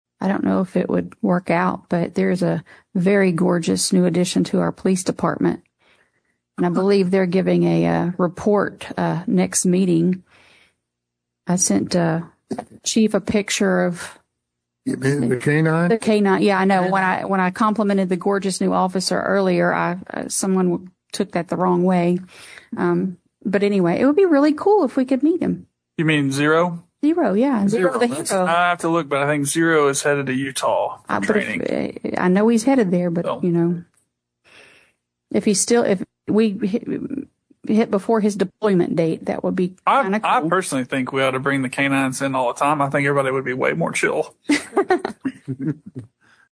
Then Woods drew laughter when he said “I personally think we ought to bring the K-9’s in all the time. I think everybody would be way more chill.”
Click below to hear comments from Melissa McIlhaney and Bryan Woods during the January 22, 2026 College Station city council meeting.